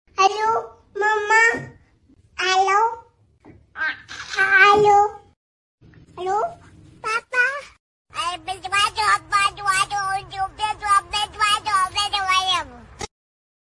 Alô Mamãe Baby Talking On Sound Effects Free Download